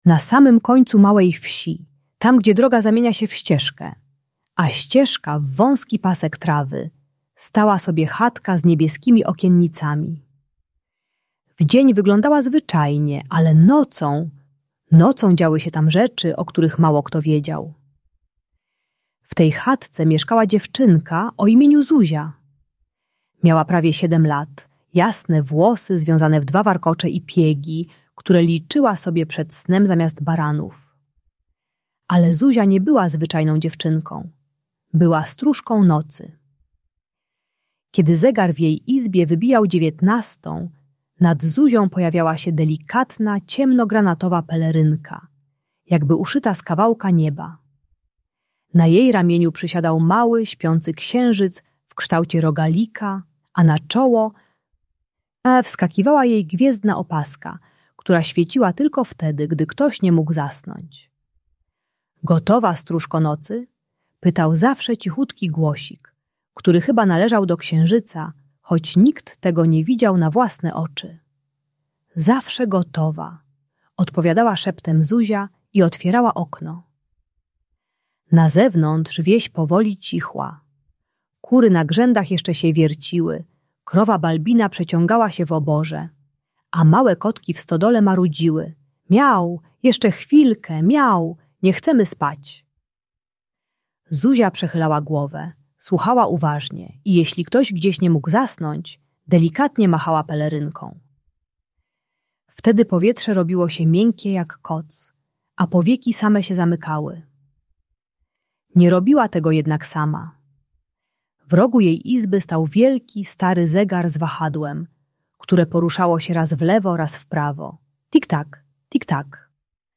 Bajka pomagająca zasnąć dla dzieci 5-7 lat, które mają problemy z zasypianiem po chorobie lub świętach. Ta audiobajka dla dziecka które nie chce spać uczy, że sen trzeba zapraszać stopniowo - każdego wieczoru kładąc się odrobinę wcześniej.